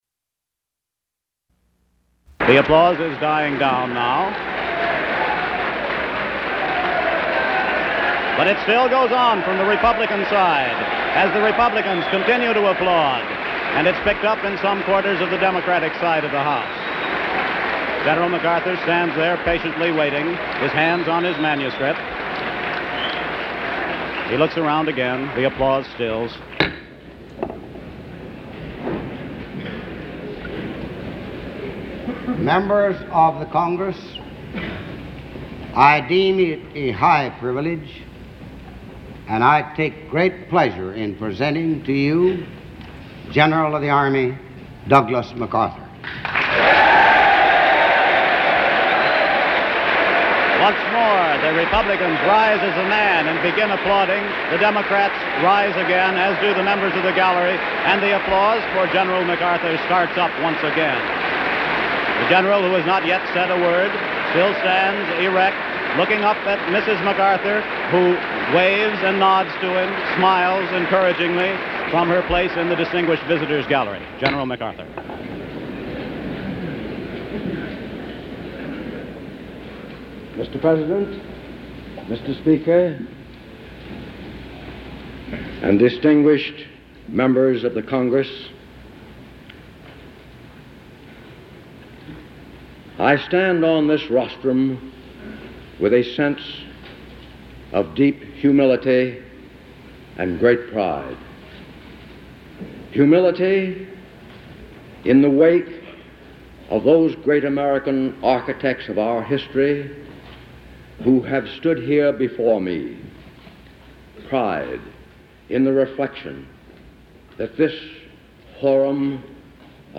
General Douglas MacArthur delivers an address before a joint session of Congress after his recall by President Truman
General Douglas MacArthur reviews his military career, the conduct of the Korean War, and says farewell as he closes his Army career. The speech later came to be known as the "Old Soldiers Never Die" speech. Commentary is provided by an unidentified reporter.